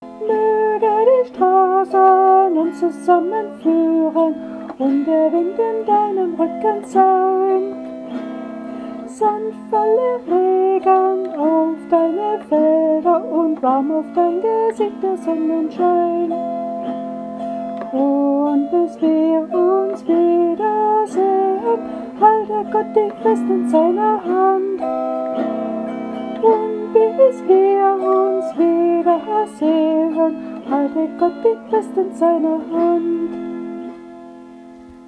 Diese Autoharp, die bisher zu bayrischer Volksmusik gespielt wurde, begleitet mich nun beim Singen der Lieder, zu denen ich Noten habe, beim Singen von …
• kirchlichen Liedern aus dem (bayrischen bzw. württembergischen) evangelischen Kirchengesangbuch